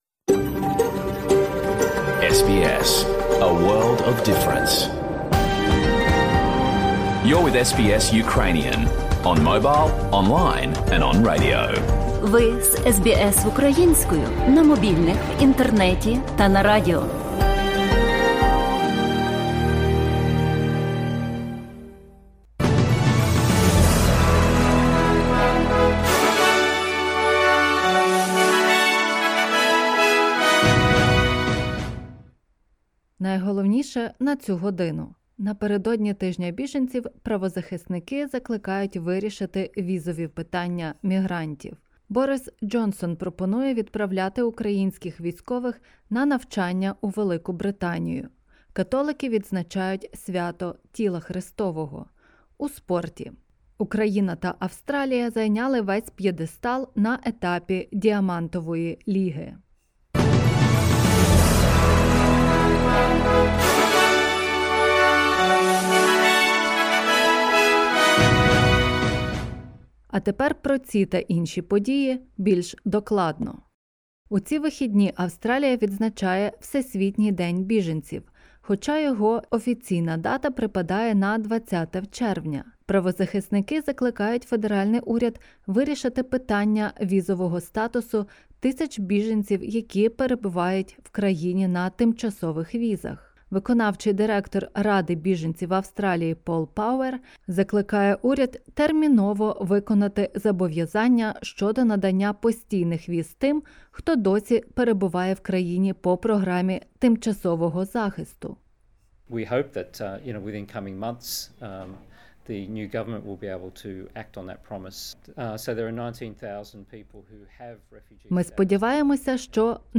SBS News in Ukrainian - 19/06/2022